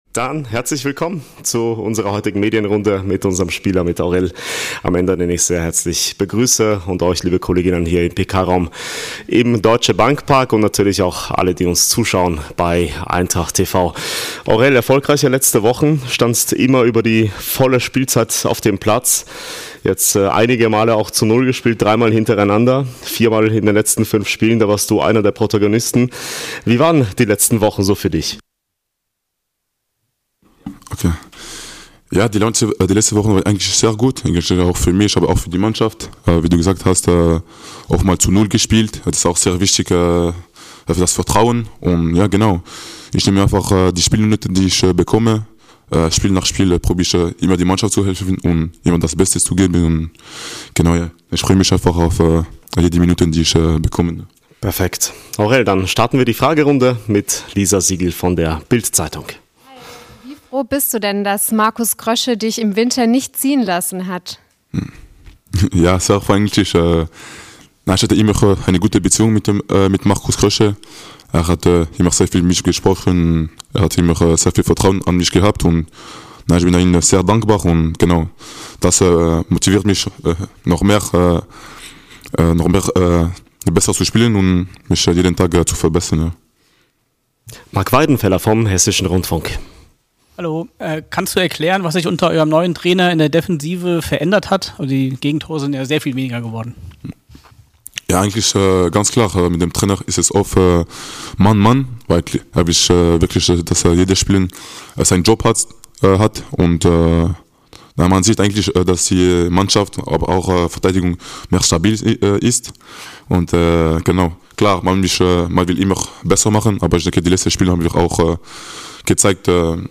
Die Pressekonferenz mit unserem Abwehrspieler Aurèle Amenda aus dem Deutsche Bank Park.